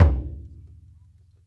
SingleHit_QAS10776.WAV